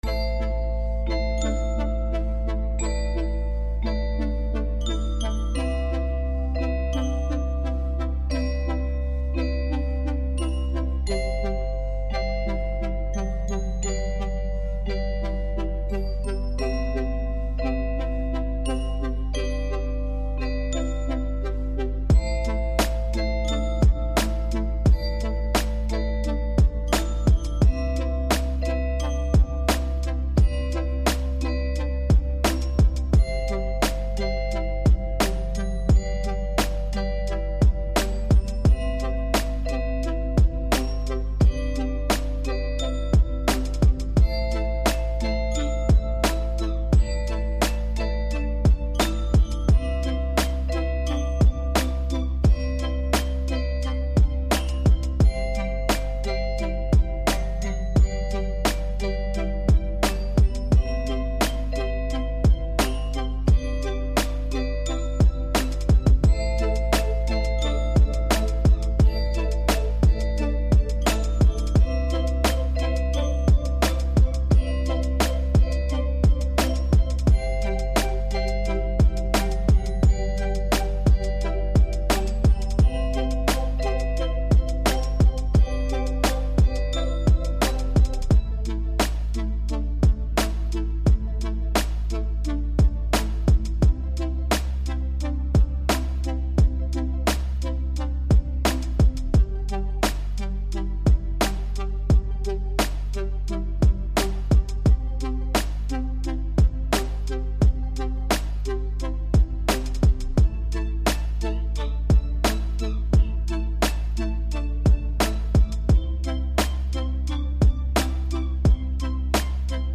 Anyway I'm using FL Studio with free Sample Pack and presets.